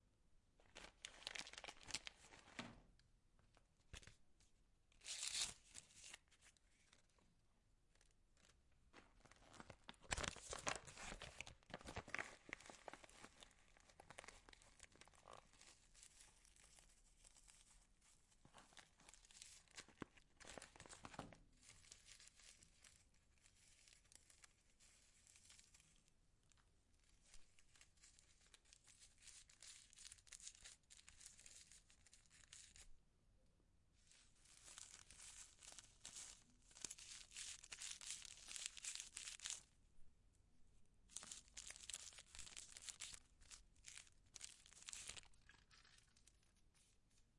描述：香烟转过来了。过滤器，纸张和烟草。最后舔了一下。 （ZOOM H6）
Tag: 吸烟 烟草 造纸 卷烟 滚动